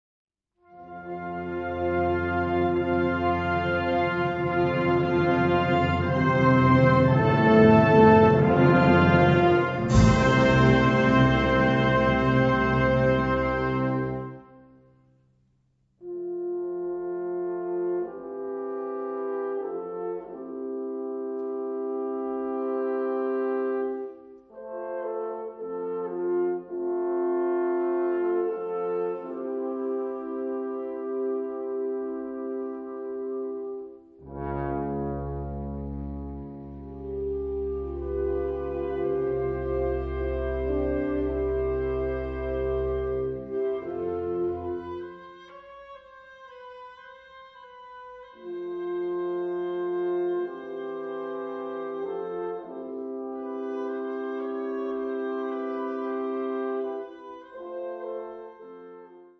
Kategorie Blasorchester/HaFaBra
Unterkategorie Ouvertüre, bearbeitet
Besetzung Ha (Blasorchester)